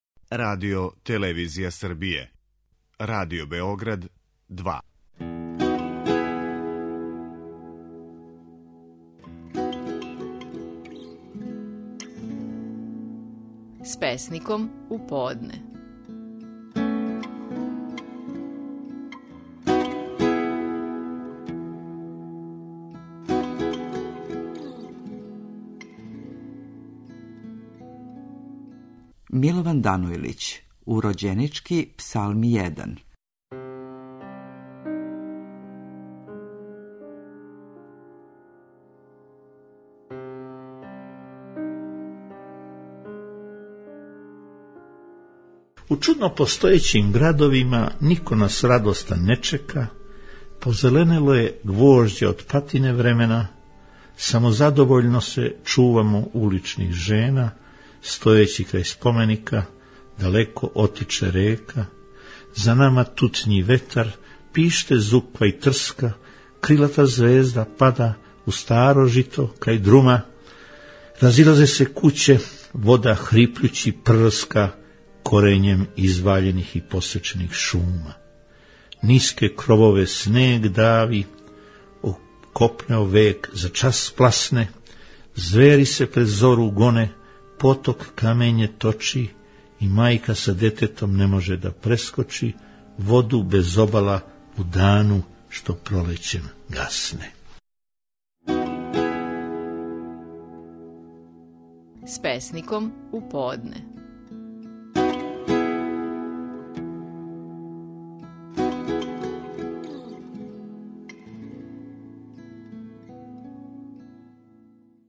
Наши најпознатији песници говоре своје стихове
Милован Данојлић говори песму „Урођенички псалми I".